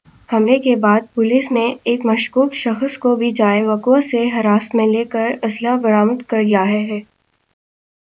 deepfake_detection_dataset_urdu / Spoofed_TTS /Speaker_12 /17.wav